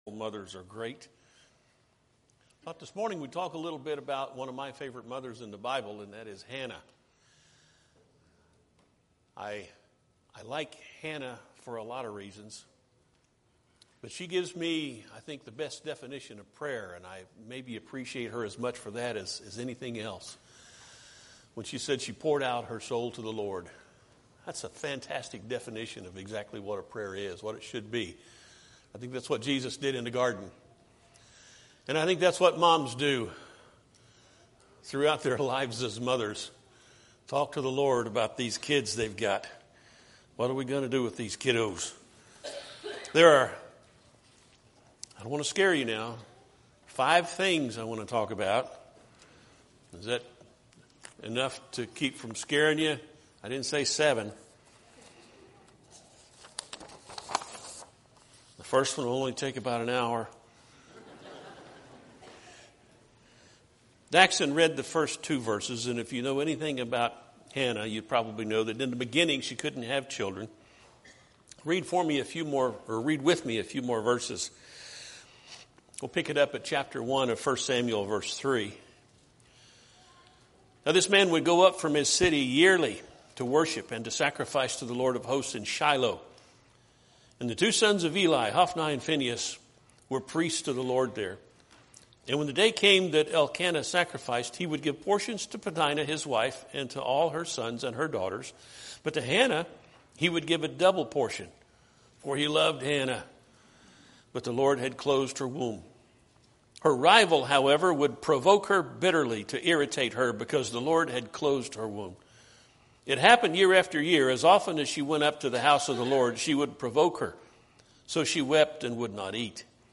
Podcasts Videos Series Sermons Hannah